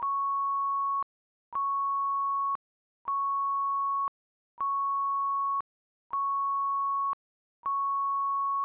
Pre-enmascaramiento:
Si se produce primero un estímulo suave y posteriormente un tono intenso, este último enmascarará igualmente al de menor amplitud, siempre  cuando estén separados en el tiempo por una diferencia menor de entre 5 y 10 ms. Como este fenómeno se presenta incluso antes de que aparezca el tono enmascarante, implica que se trata de un proceso más complejo que el Post-enmascaramiento.
Ejemplo 2. Los tonos están separados por 10 ms (en la zona frontera) y se puede apreciar un poco de pre-enmascaramiento.